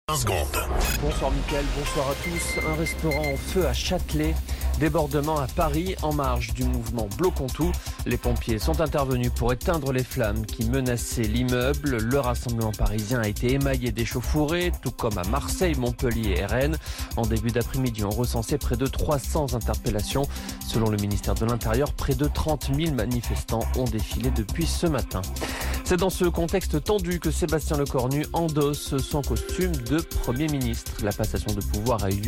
Flash Info National 10 Septembre 2025 Du 10/09/2025 à 16h10 .